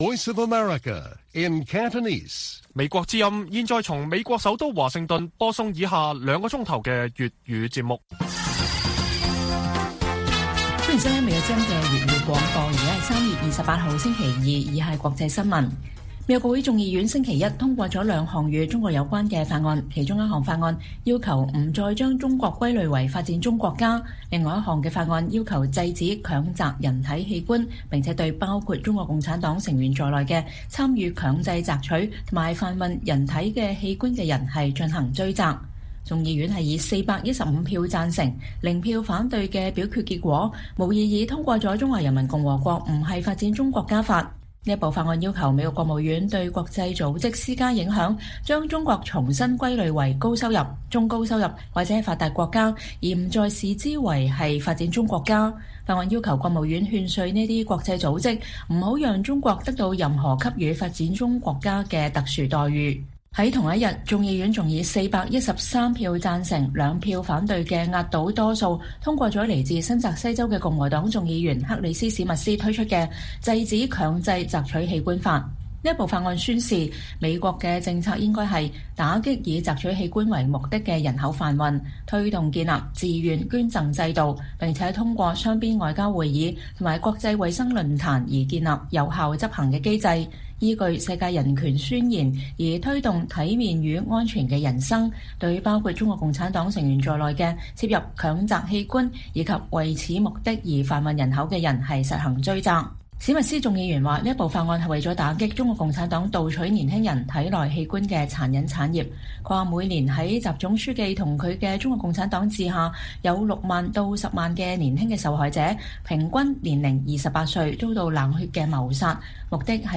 粵語新聞 晚上9-10點: 英國港人高峰會與國會議員進行交流